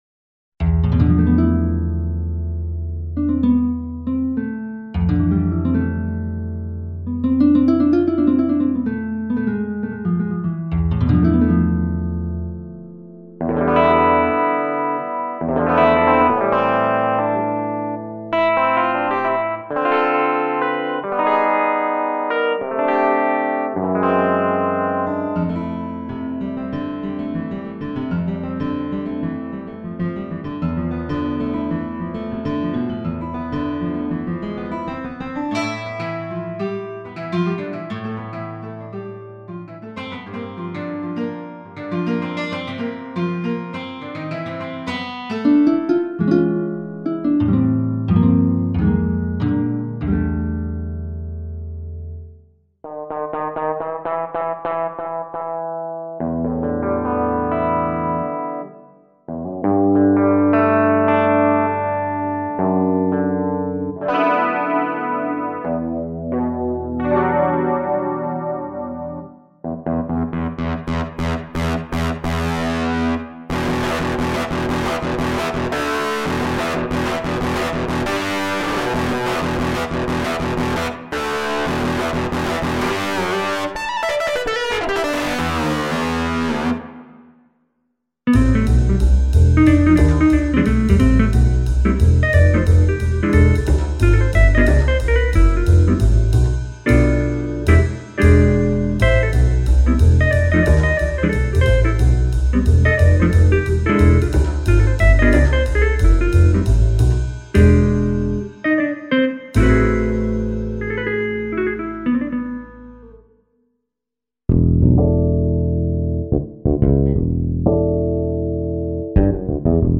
ベース＆ギター